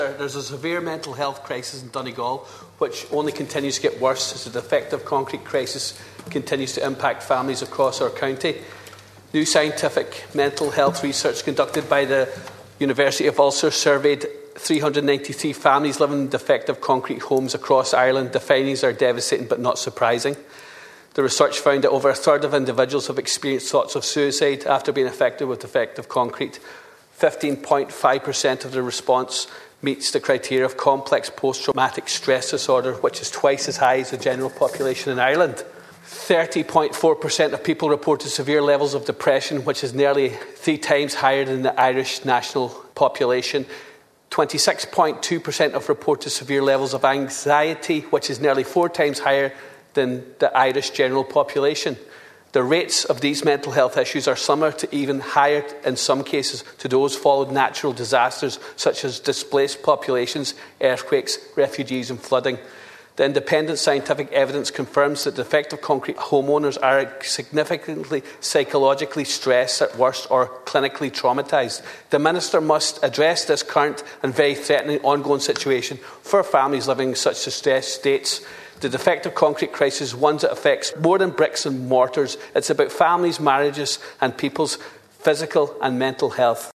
That’s according to Deputy Charles Ward who presented a summary of the findings of a recent Ulster University survey looking at how the defective blocks crisis is affected the health of those affected.
Speaking during statements on mental health last evening, he told TDs the figures are frightening, and in places, the stress on homeowners is akin to the impact of major natural disasters……..